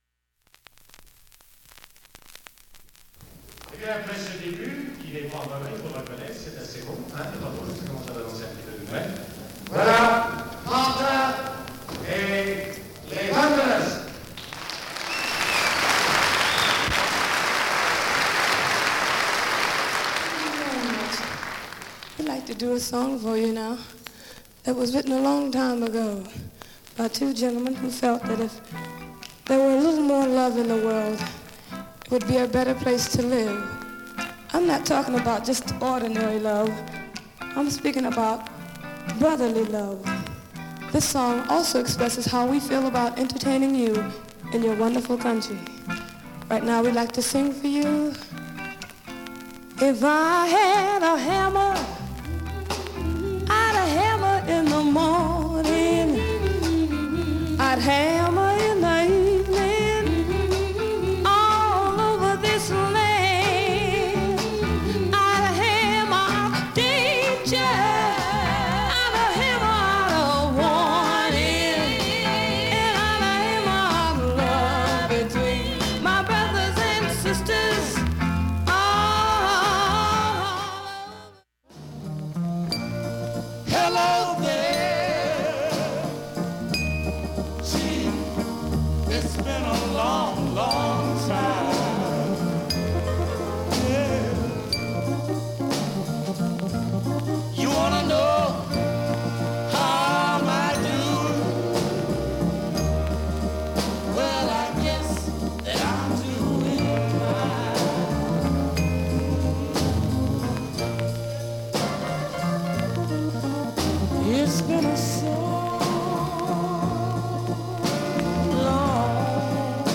下記プツもかすかなレベルです。
普通に聴けます音質良好全曲試聴済み。
単発のかすかなプツが１３箇所
のコンサートの臨場感あふれる録音。